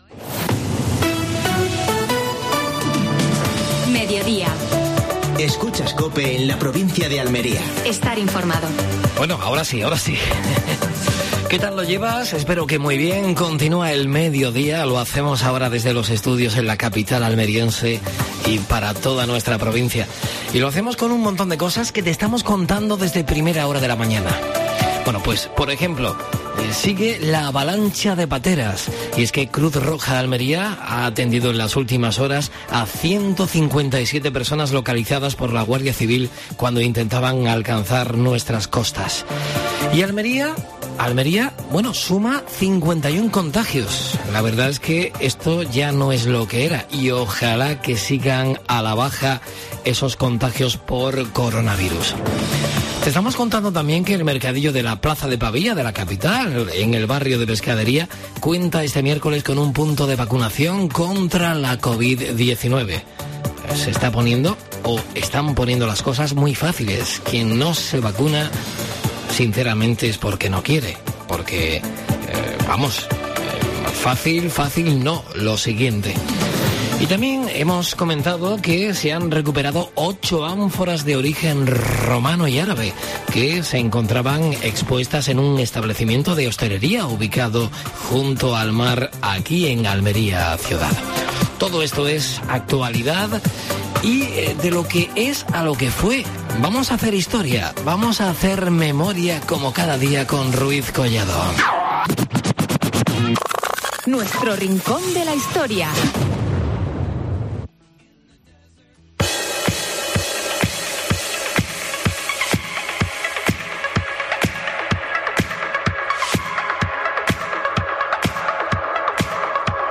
Actualidad en Almería. Entrevista